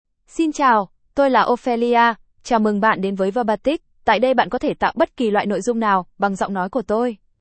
OpheliaFemale Vietnamese AI voice
Ophelia is a female AI voice for Vietnamese (Vietnam).
Voice sample
Listen to Ophelia's female Vietnamese voice.
Female